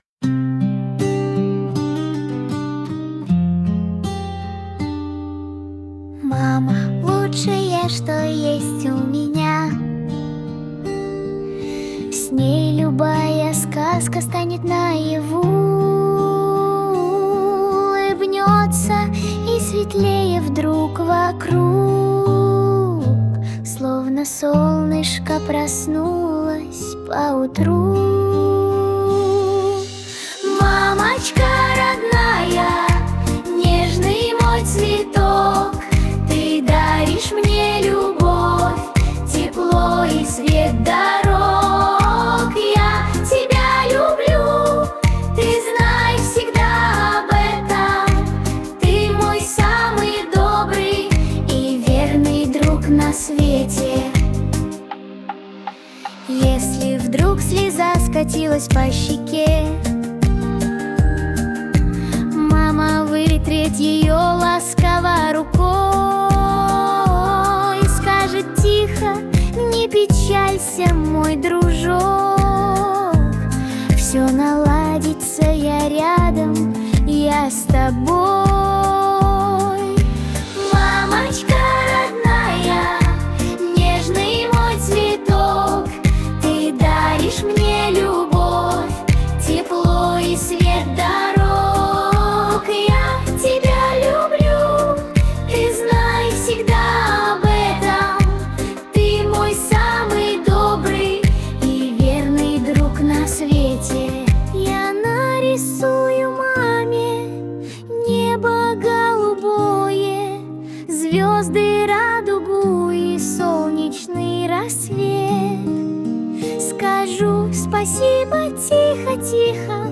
• Качество: Хорошее
• Категория: Детские песни
Новая детская песня про маму — тепло, просто, искренне.